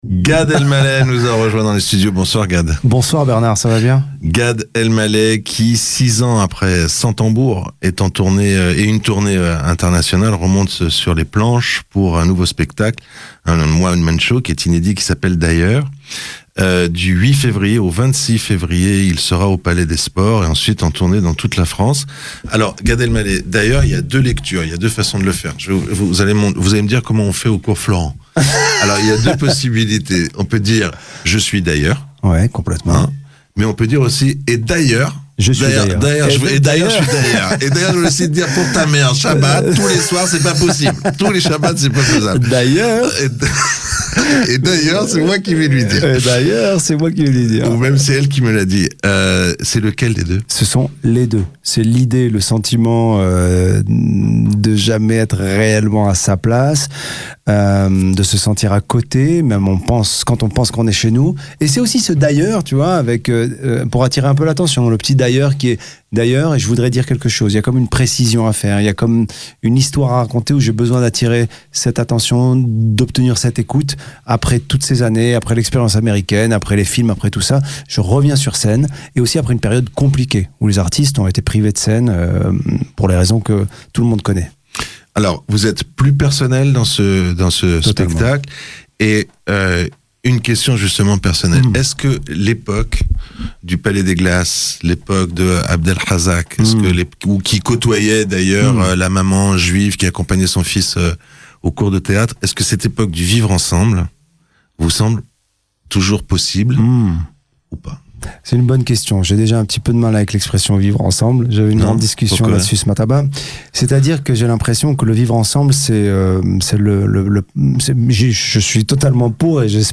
Un moment drôle, agréable, bienveillant.